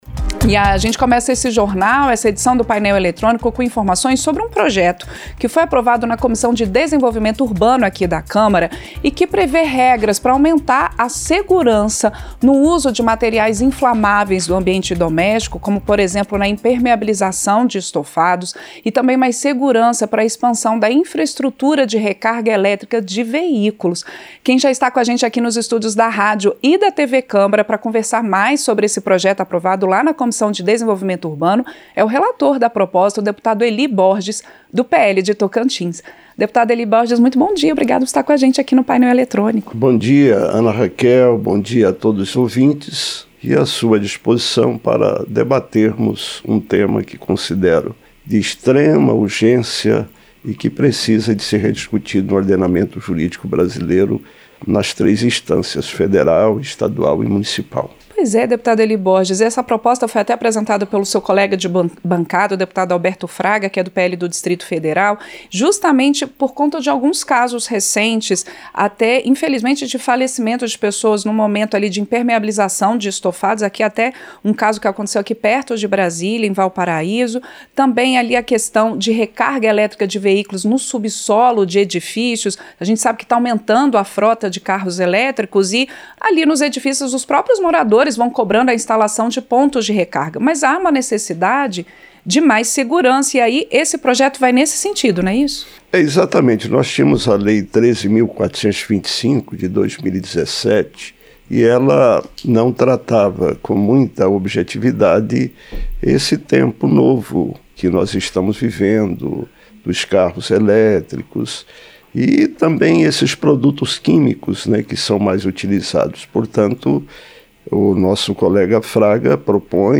Entrevista - Dep. Eli Borges (PL-TO)